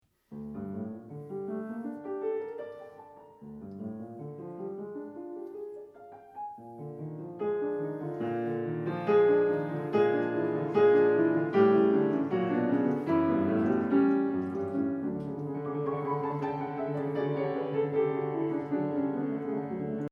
And listen to this, a variation of the four notes, over and over again…